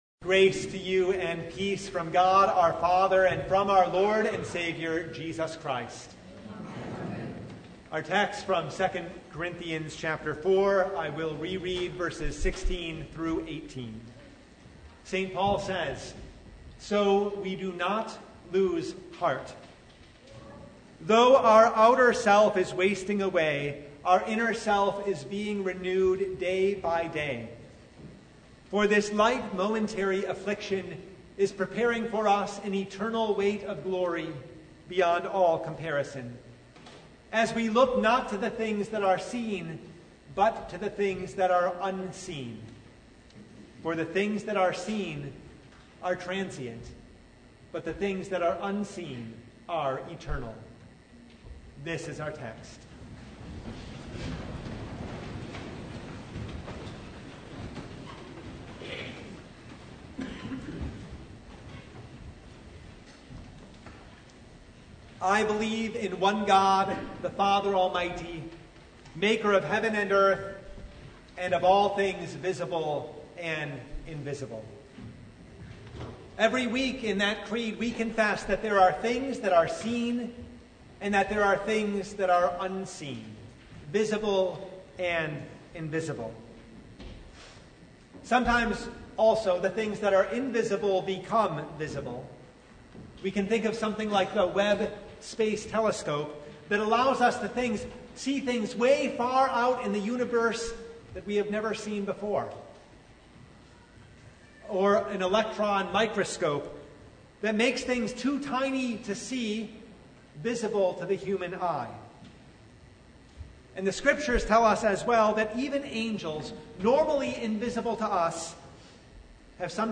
2 Corinthians 4:13-5:1 Service Type: Sunday God’s Word helps us see and understand the world